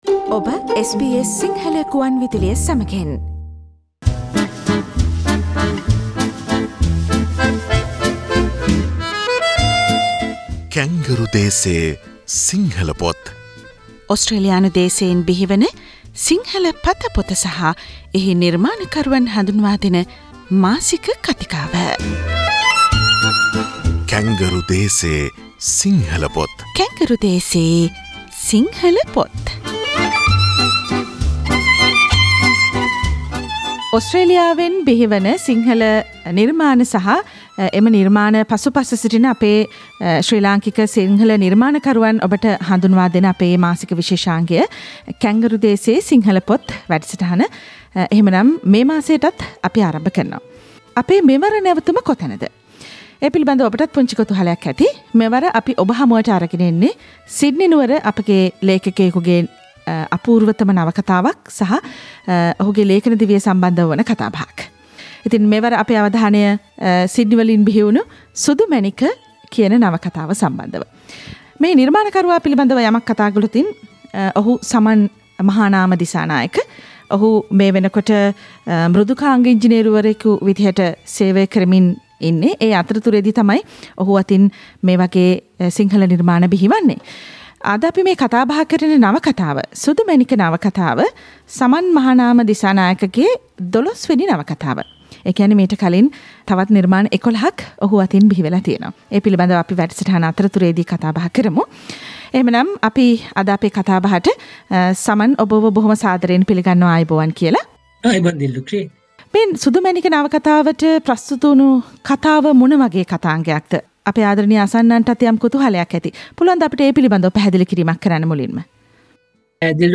ඕස්ට්‍රේලියානු දේශයෙන් බිහිවන සිංහල ග්‍රන්ථ විමර්ශනය කෙරෙන මාසික කතිකාව 'කැන්ගරු දේසේ සිංහල පොත්' වැඩසටහන මාසයේ අවසාන අඟහරුවාදා SBS සිංහල වැඩසටහනින් ඔබට සවන්දිය හැකියි....